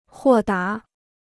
豁达 (huò dá): optimistic; sanguine.